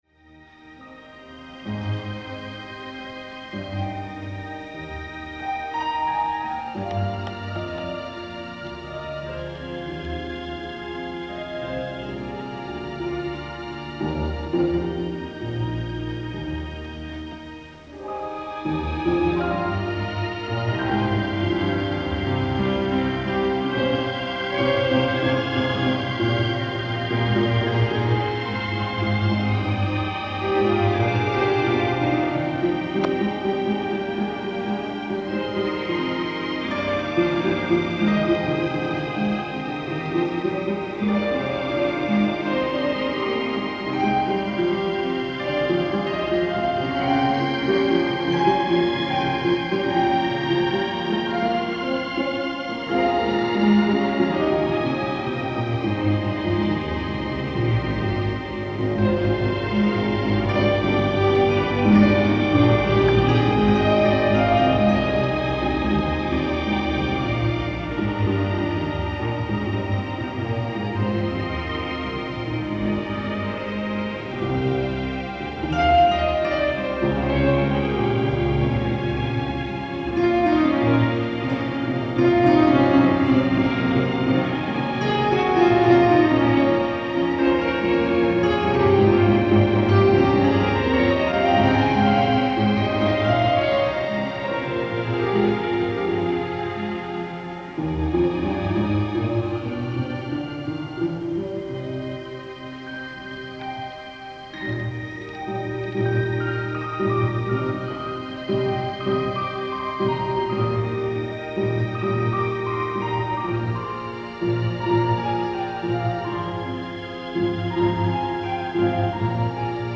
ライブ・アット・ＤＡＲ コンスティテューション・ホール、 ワシントンDC
※試聴用に実際より音質を落としています。